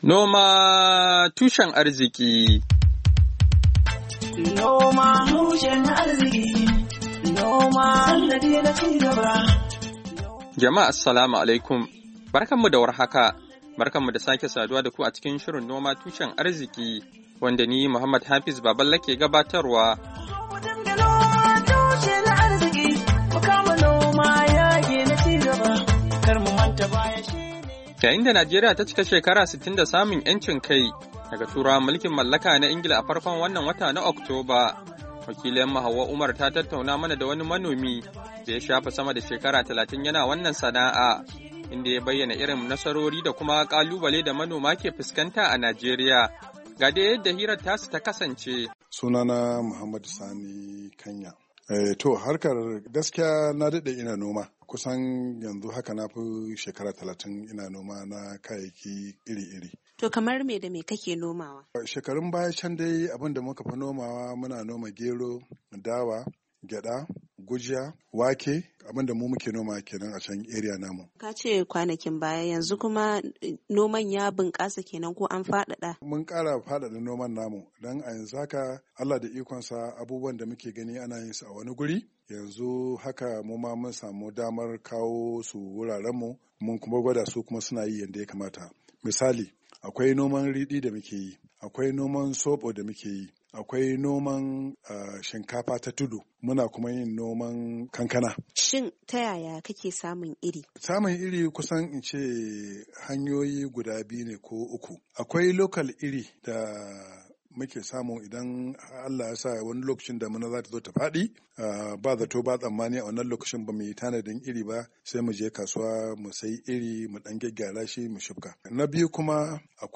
Hira Da Manomi Kan Irin Kalubalen Da suke Fuskanta a Najeriya - 6'15